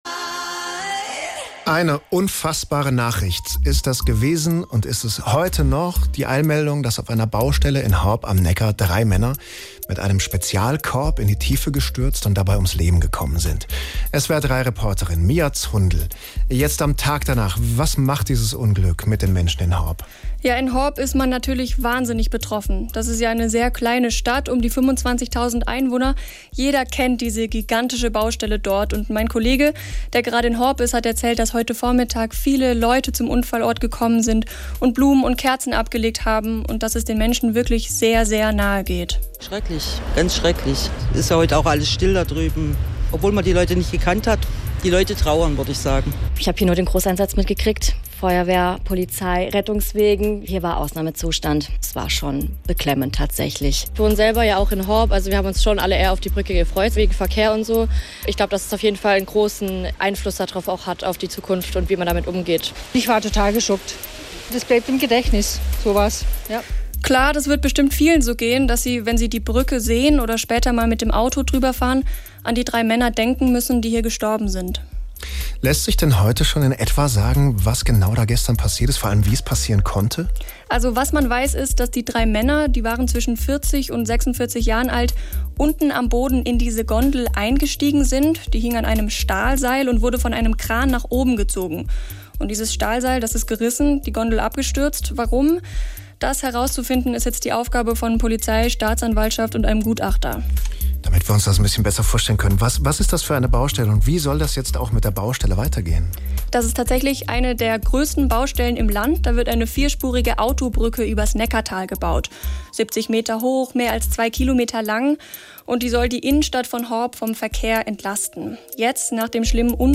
Das ganze Gespräch